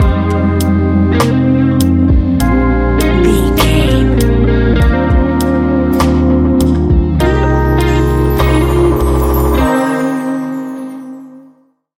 Ionian/Major
A♯
laid back
Lounge
sparse
new age
chilled electronica
ambient
atmospheric
instrumentals